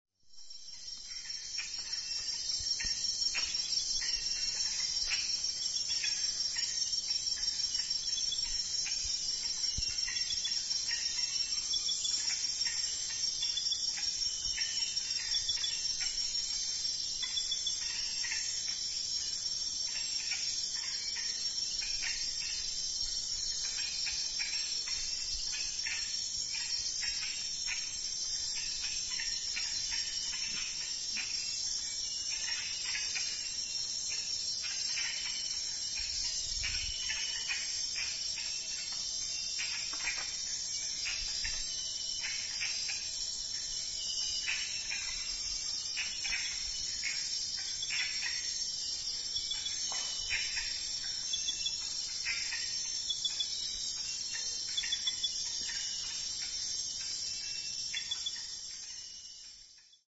Red eyed tree frogs- you know, those cute calendar froggies you see everywhere- this is what they sound like.
redeyedtreefrogs.mp3